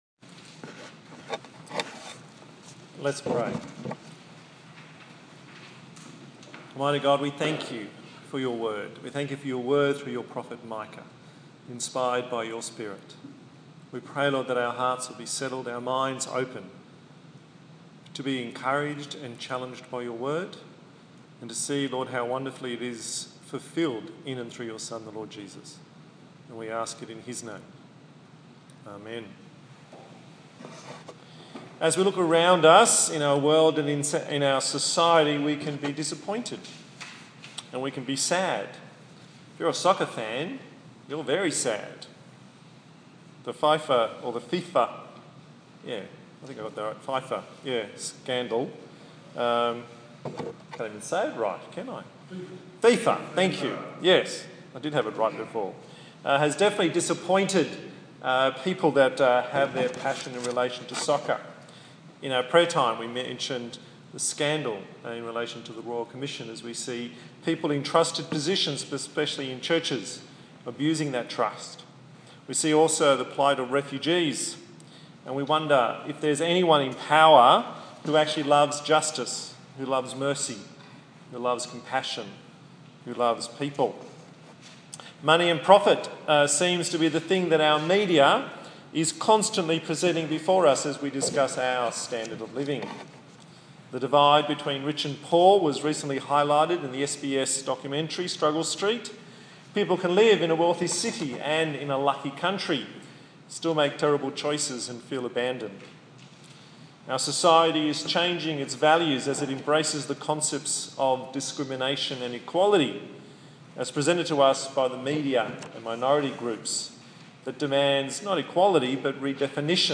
The Seventh in the sermon series on Micah